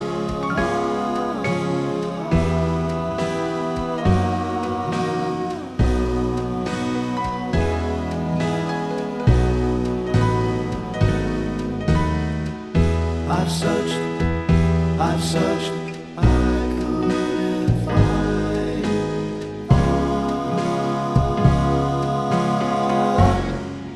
Minus Guitars Pop